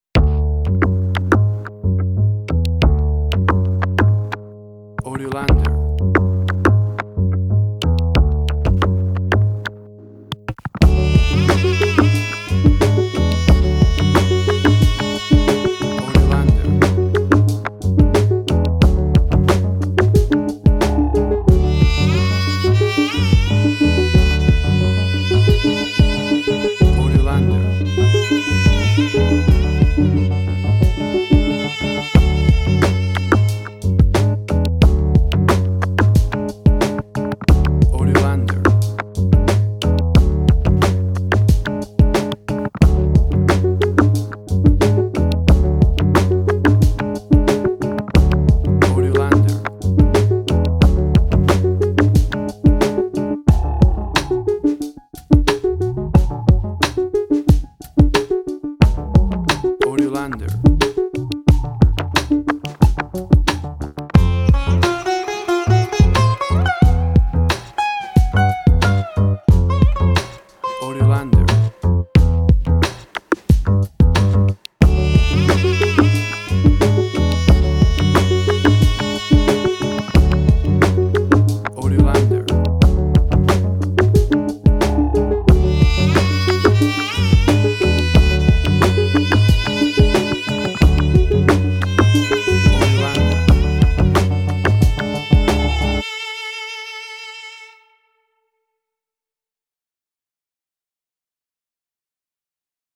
emotional music
Tempo (BPM): 90